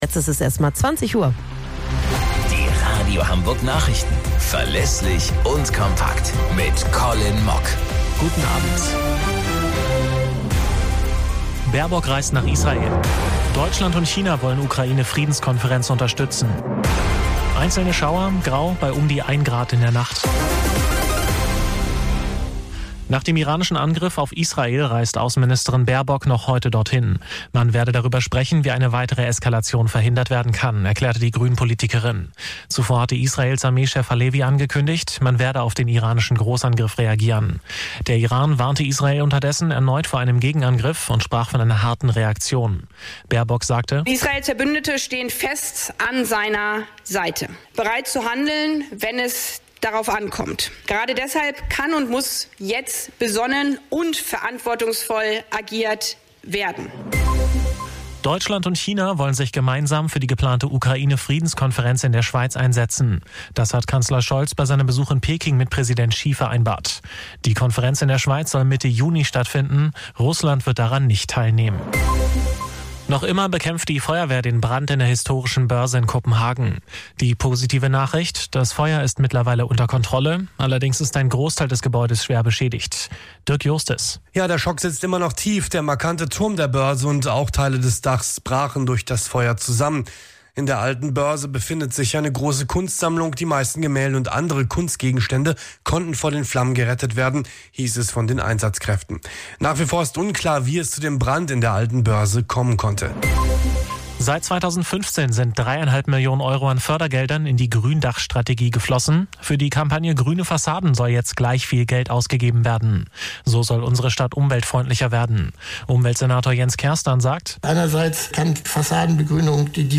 Radio Hamburg Nachrichten vom 02.06.2024 um 21 Uhr - 02.06.2024